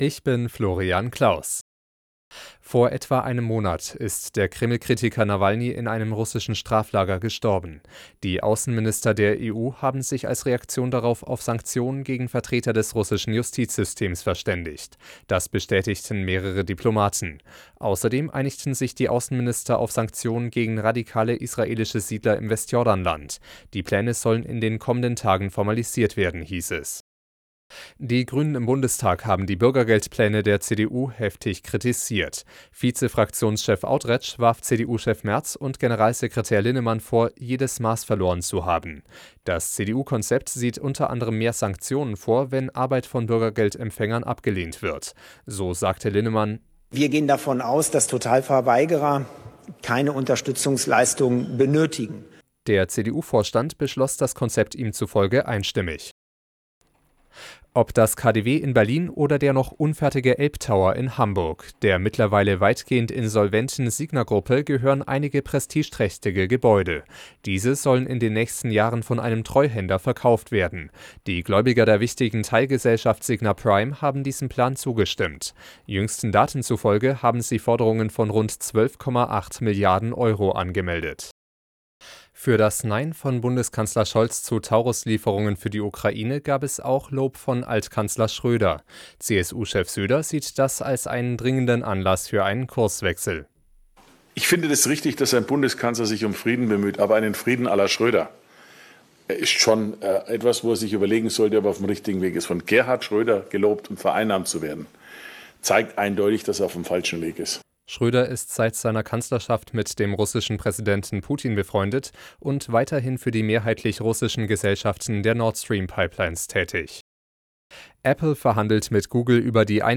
Die aktuellen Nachrichten von Radio Arabella - 18.03.2024